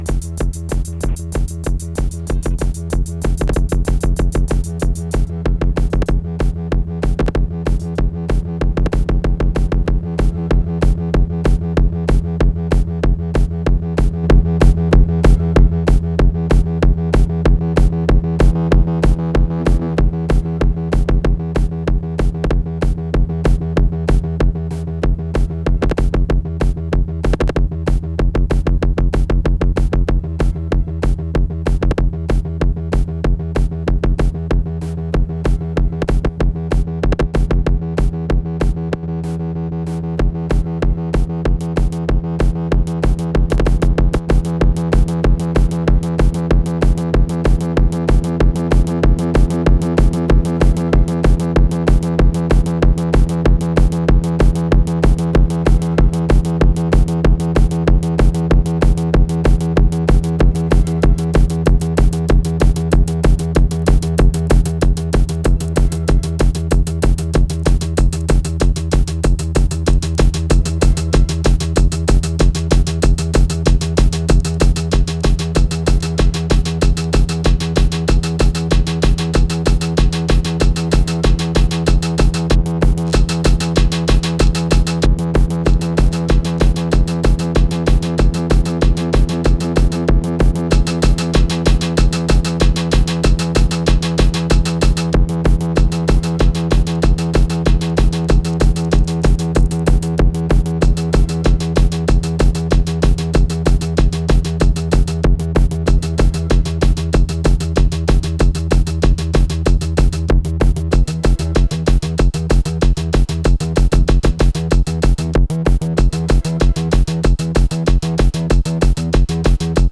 Hypnotischer Industrial Tekno bei 190bpm.